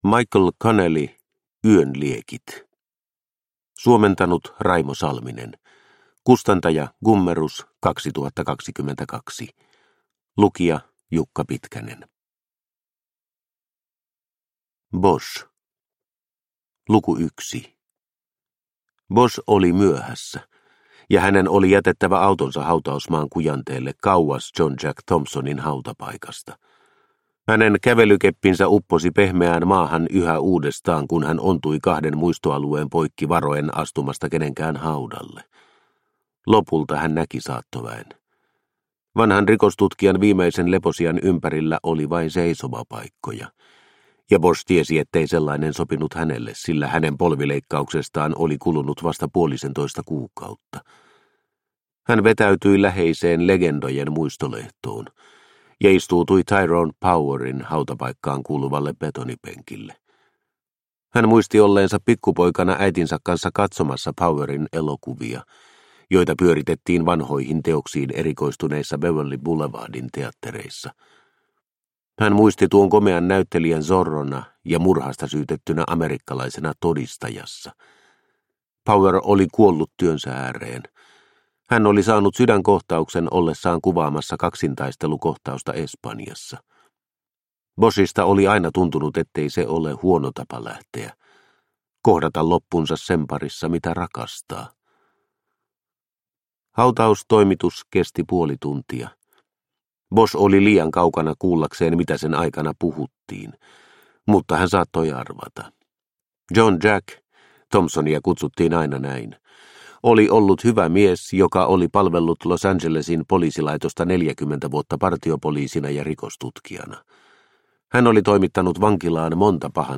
Yön liekit – Ljudbok